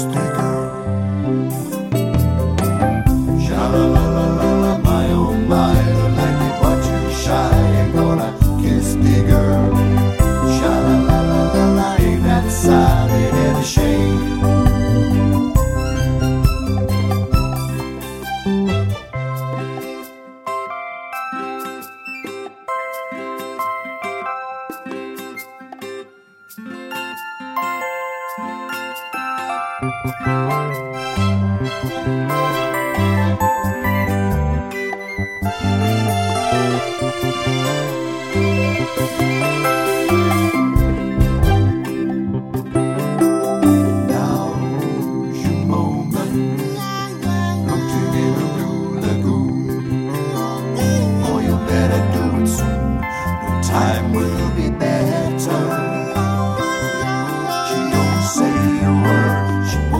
With Count In Soundtracks 2:44 Buy £1.50